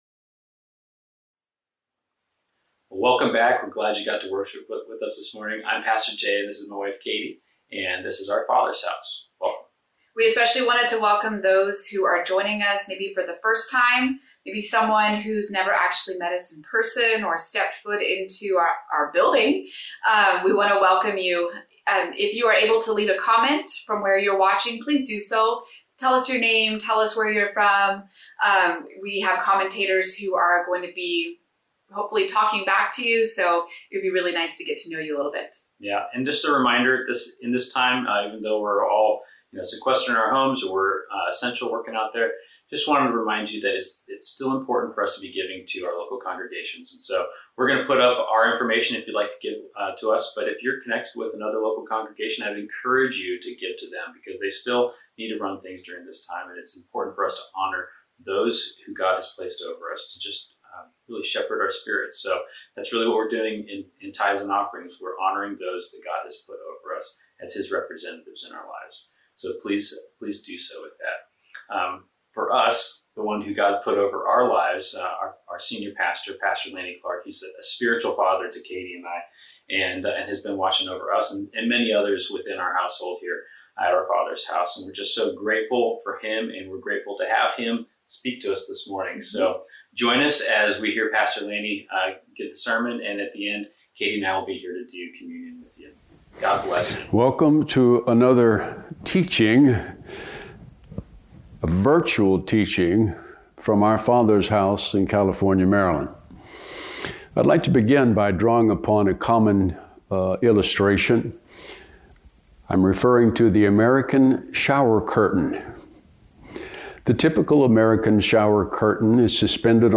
We welcome you to the Our Father's House online service.